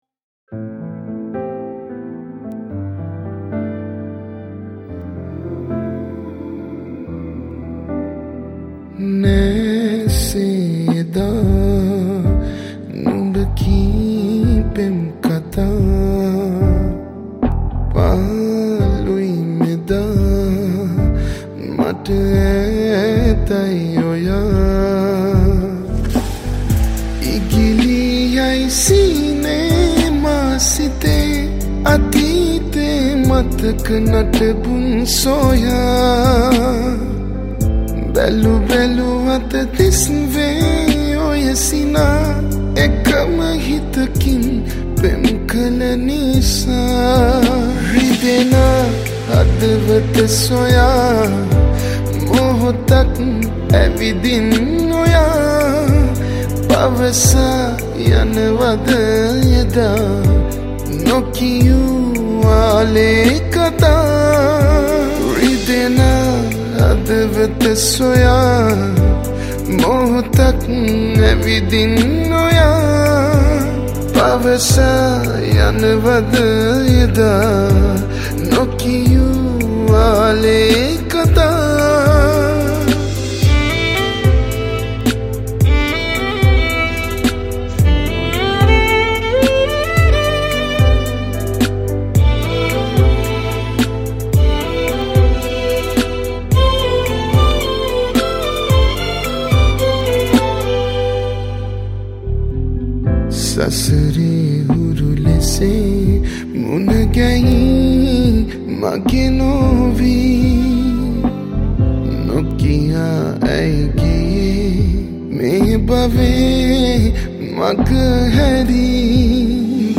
Violin/Viola